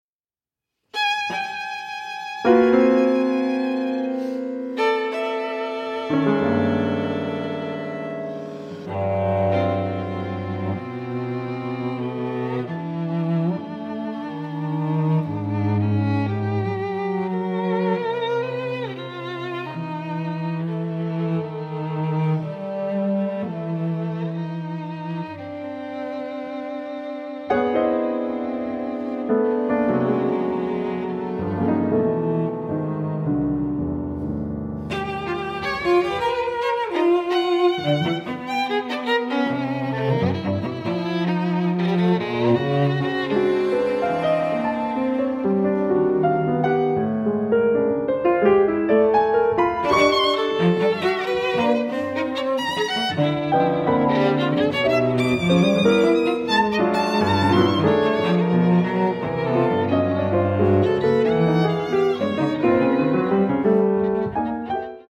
chamber works for various instrumentations
I. Moderato